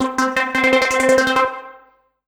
14SYNT01  -L.wav